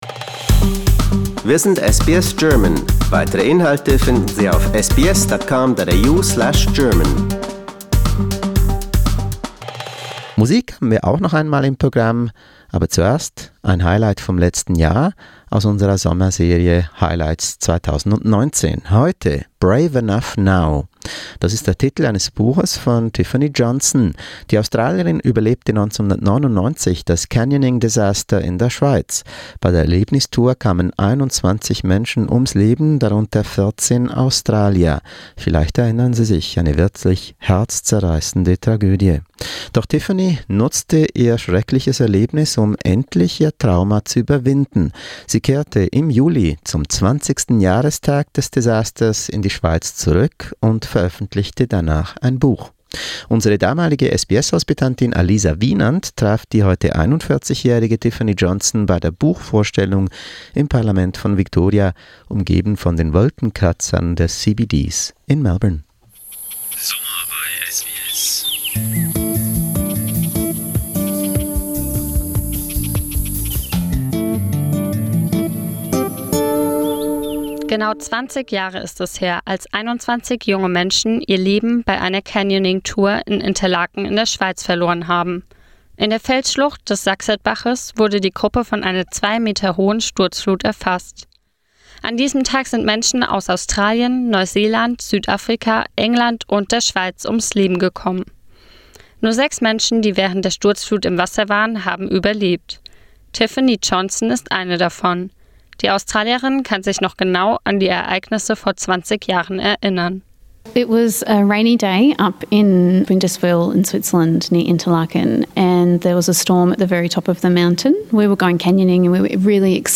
Here's an English summary of the memorable interview.